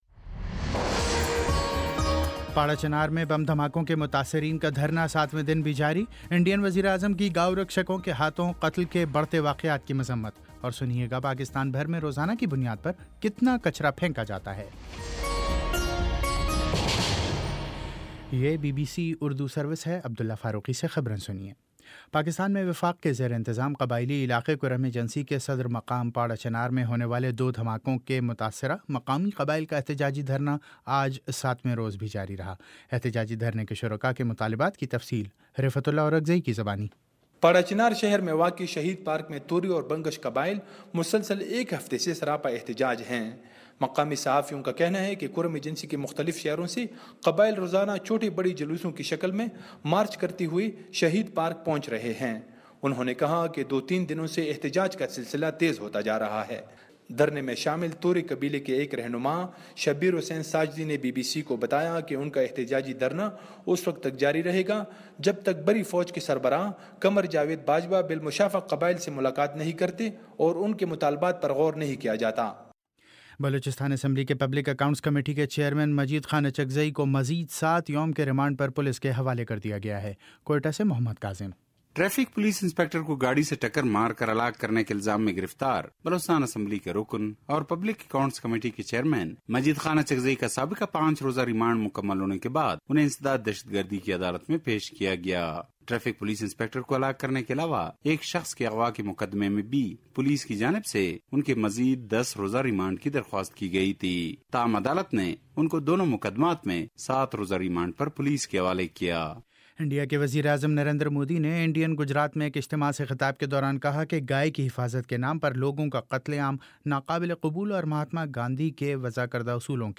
جون 29 : شام چھ بجے کا نیوز بُلیٹن